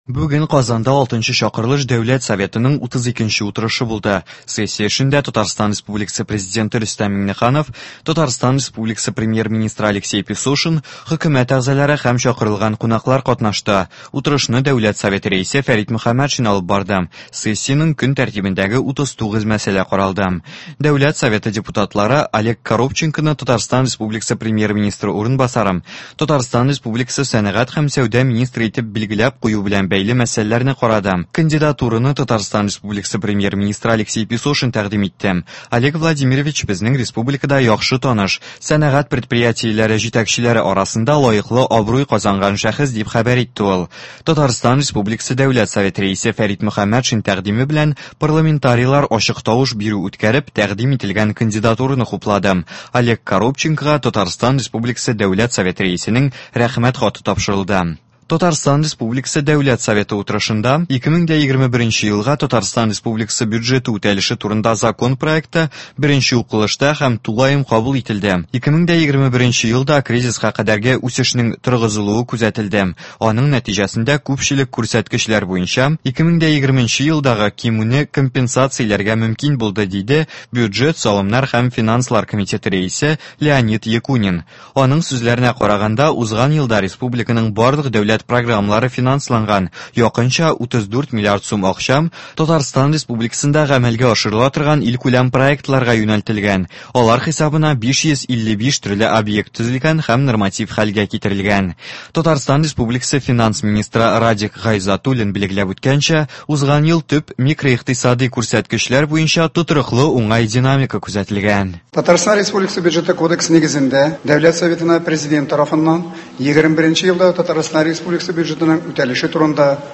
В эфире специальный информационный выпуск , посвященный 32 заседанию Государственного Совета Республики Татарстан 6-го созыва.